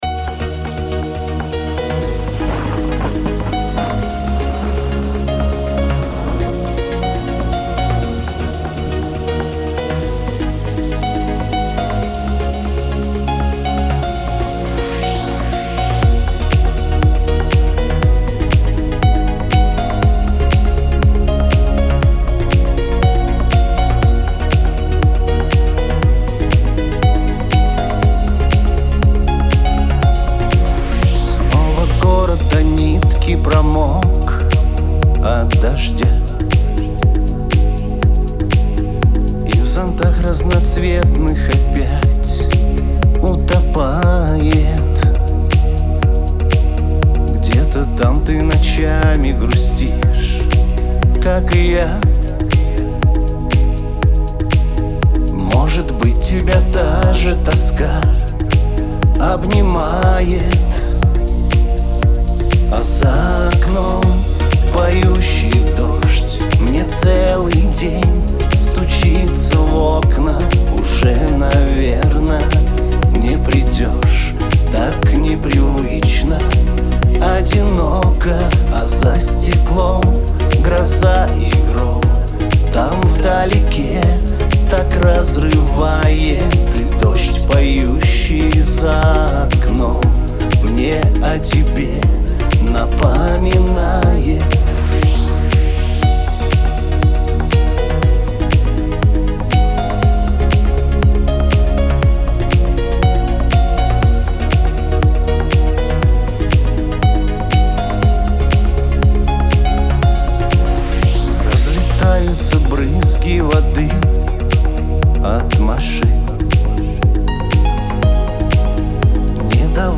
диско
pop